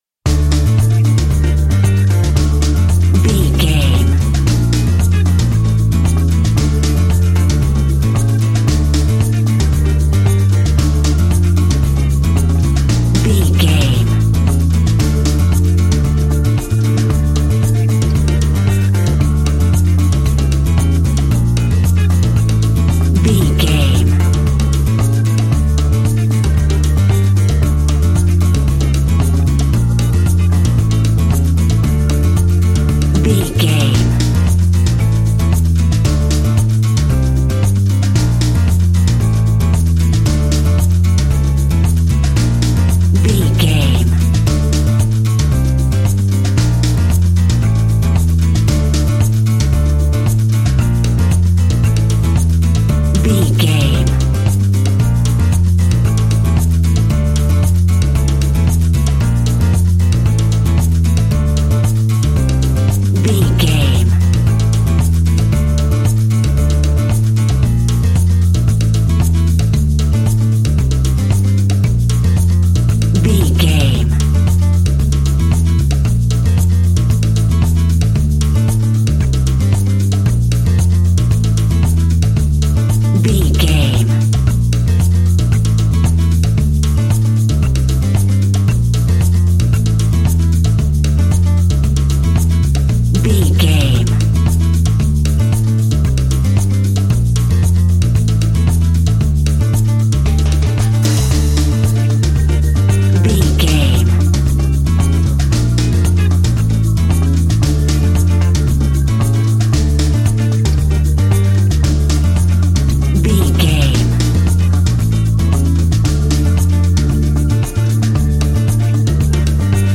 An exotic and colorful piece of Espanic and Latin music.
Aeolian/Minor
flamenco
maracas
percussion spanish guitar